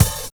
2510L BD.wav